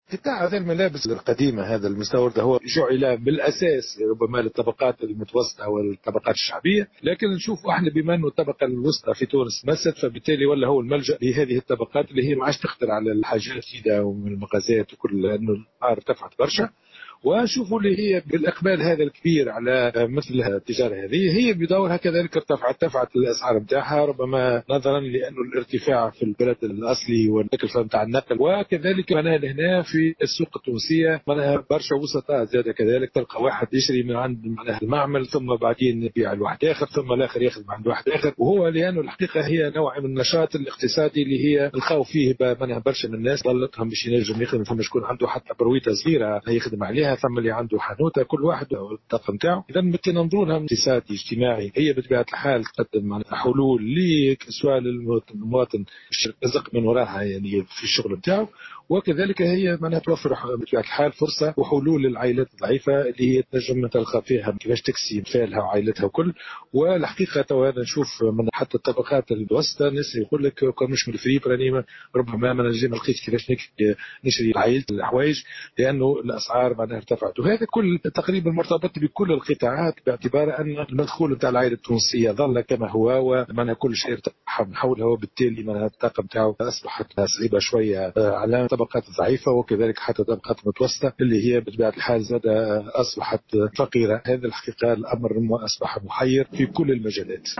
S’exprimant au micro de Tunisie Numérique